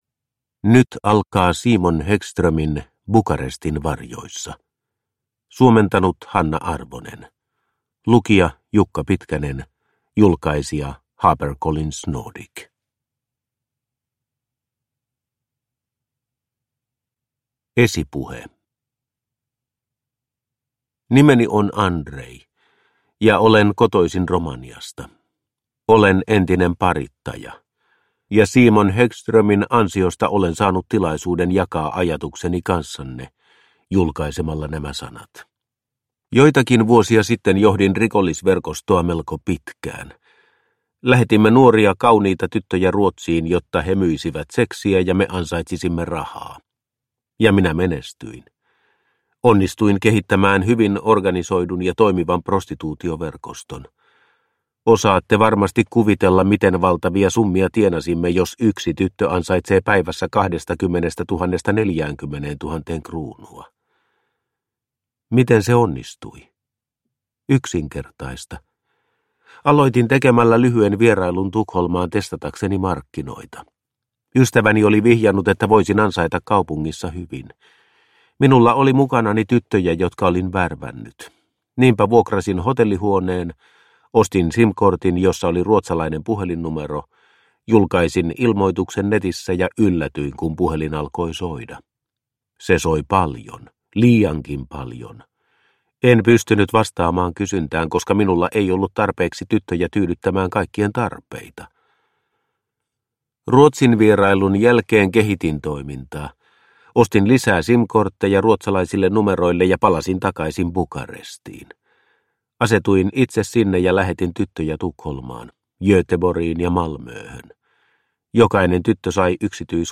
Bukarestin varjoissa – Ljudbok – Laddas ner